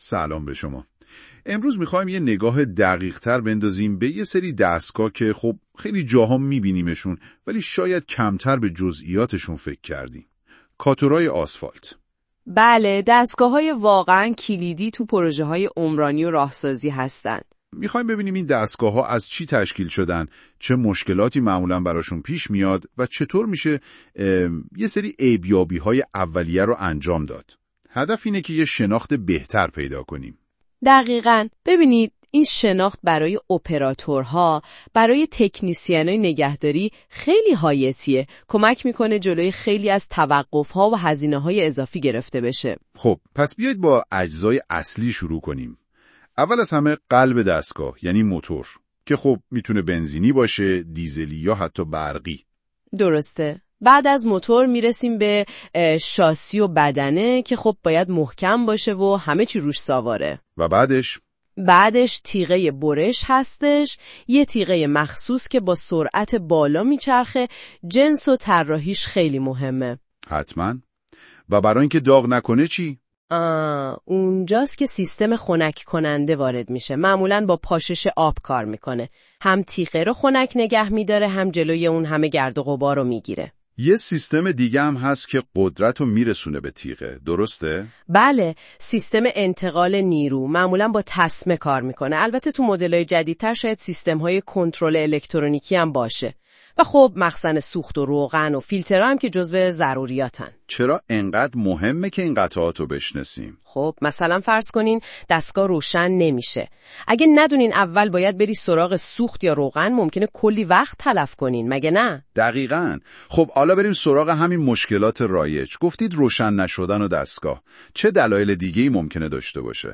اگر وقت و حوصله کافی برای خواندن متن رو ندارید به سادگی میتونید با پخش فایل صوتی زیر محتوای مقاله رو در قالب یک مکالمه جذاب بشنوید.